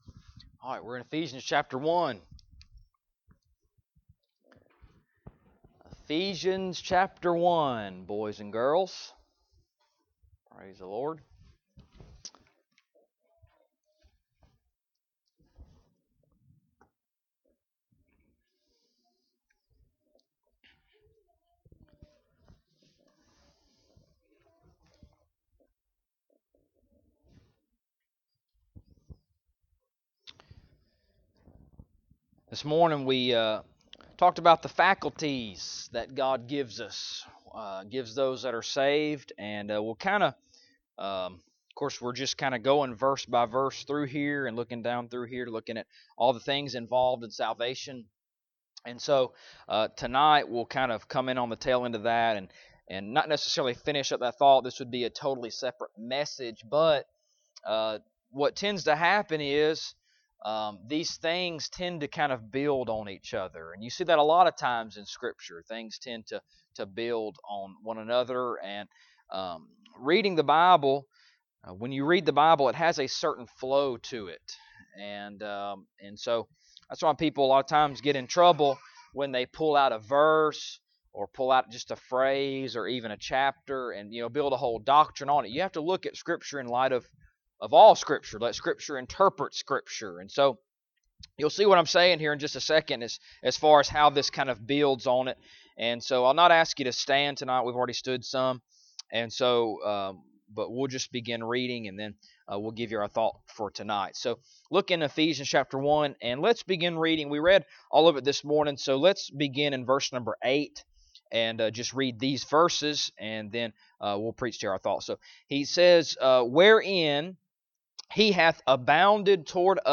Ephesians Passage: Ephesians 1:8-10 Service Type: Sunday Evening Topics